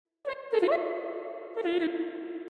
Windows USB Sound Blob sound effects free download